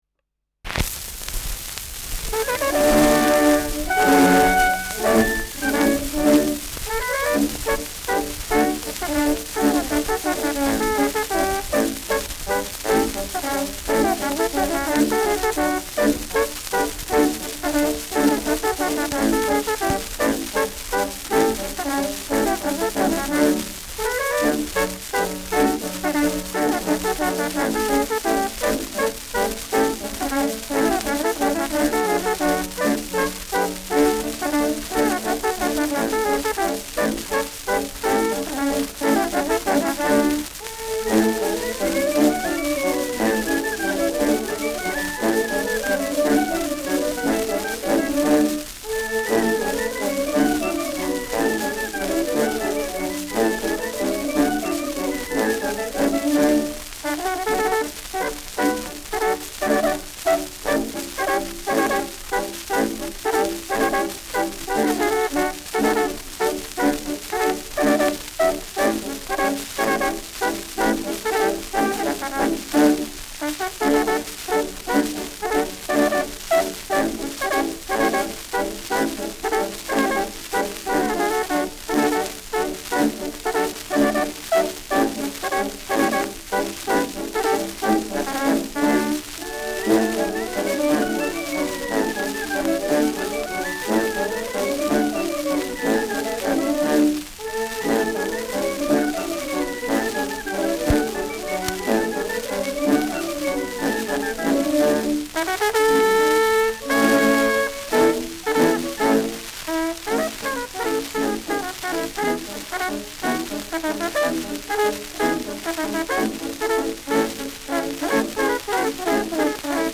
Schellackplatte
präsentes Rauschen : abgespielt : leiert : gelegentliches Knacken
Stadtkapelle Fürth (Interpretation)